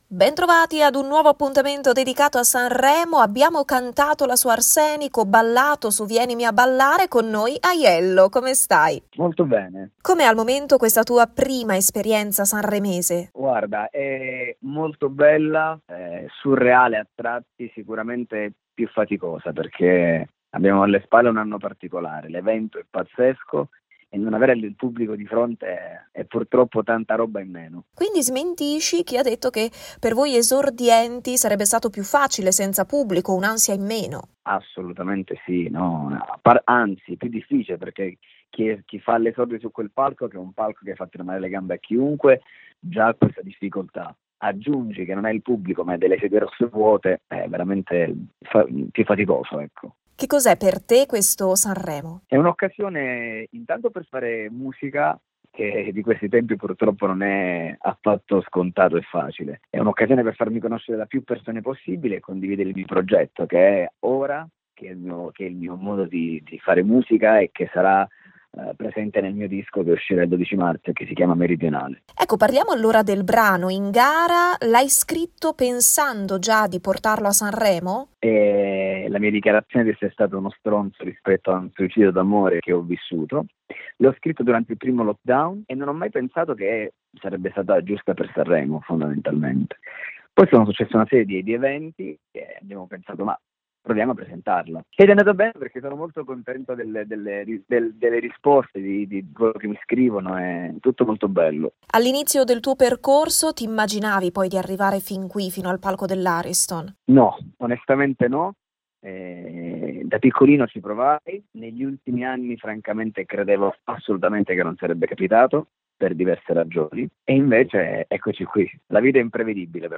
Sanremo 2021: Radio Pico intervista Aiello
Il cantante di Cosenza, da milioni di views, è stato protagonista di una bella chiacchierata al microfono di Radio Pico: